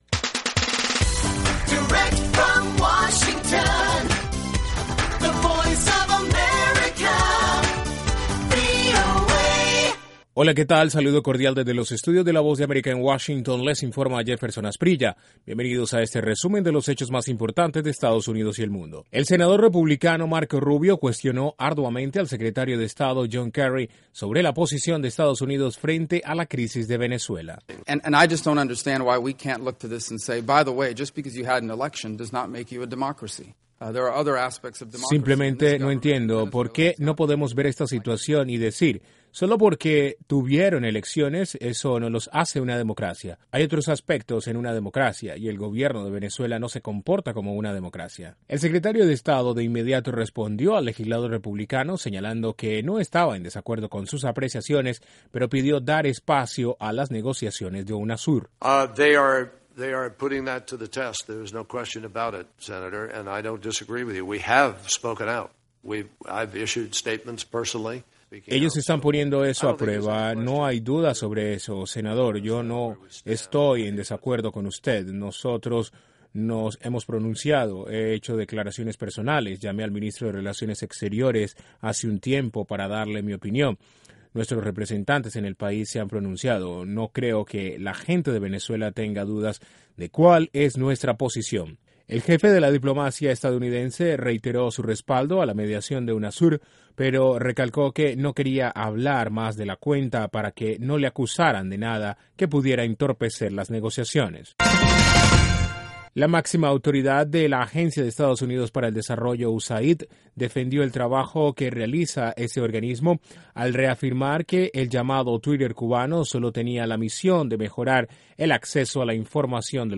RESUMEN NOTICIAS MARTES 08 ABRIL 2014 PM